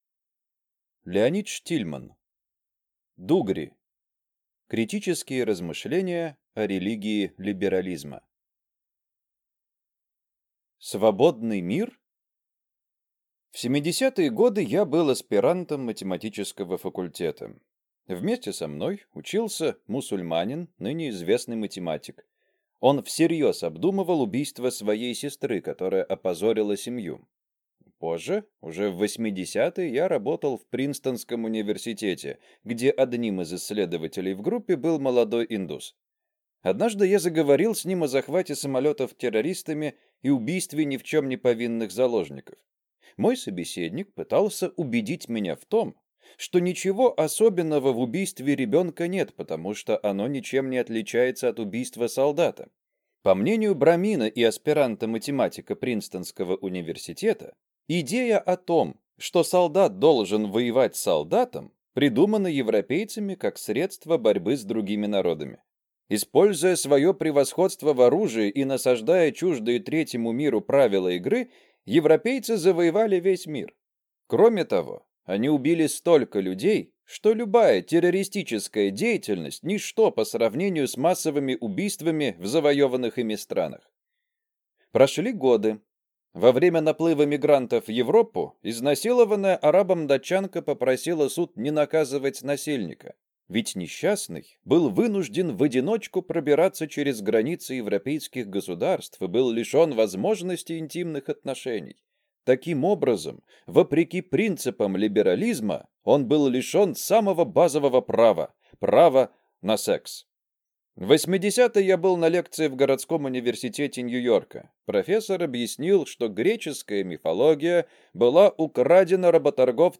Аудиокнига Дугри | Библиотека аудиокниг